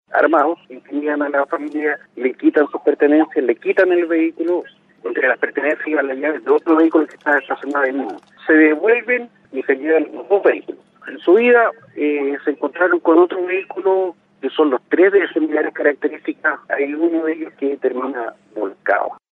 Por fortuna, la víctima no resultó con lesiones y los ladrones continuaron con su escape.